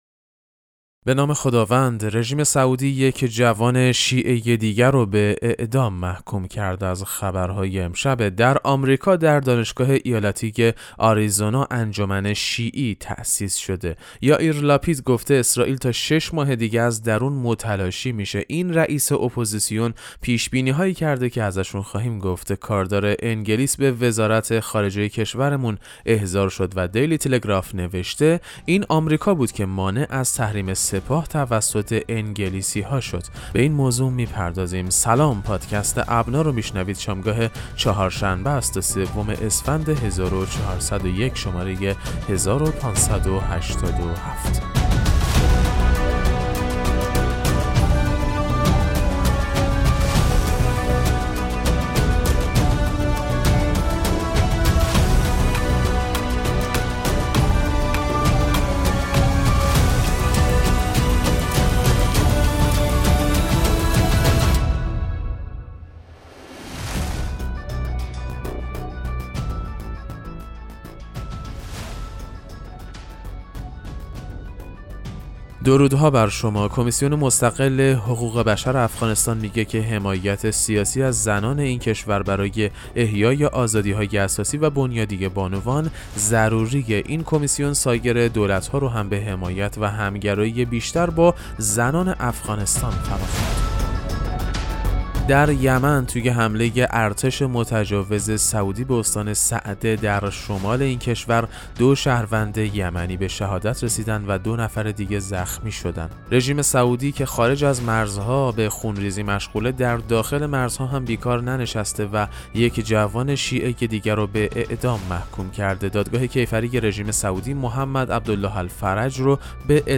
پادکست مهم‌ترین اخبار ابنا فارسی ــ سوم اسفند1401